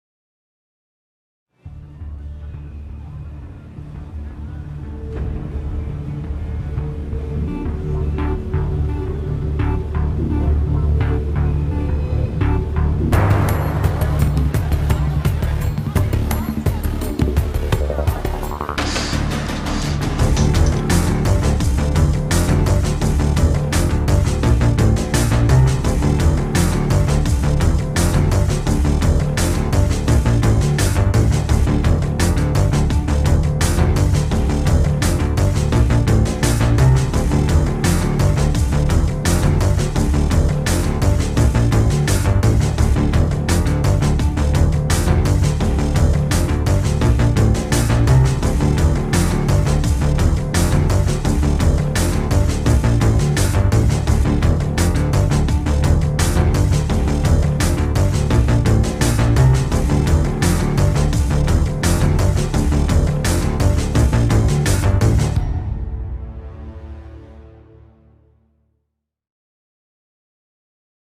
tema dizi müziği